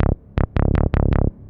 hous-tec / 160bpm / bass
tb303-2.wav